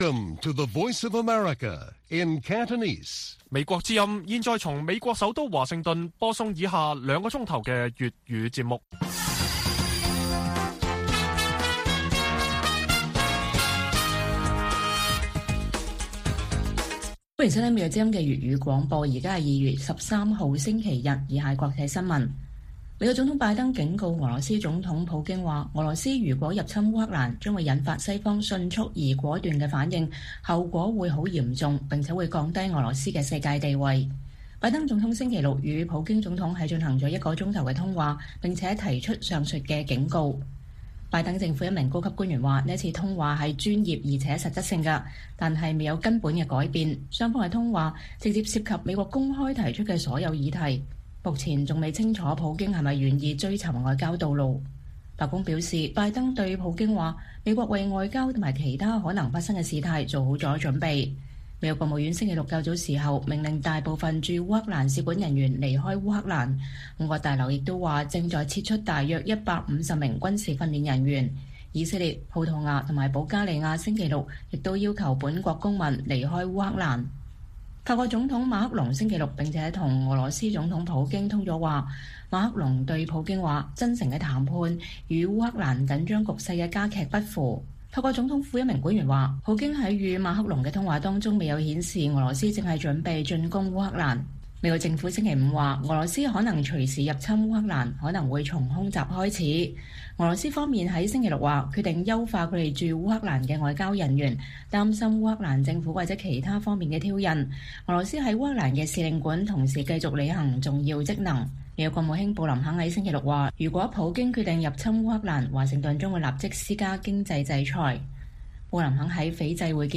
粵語新聞 晚上9-10點：拜登警告普京：入侵烏克蘭將引發迅速果斷的西方反應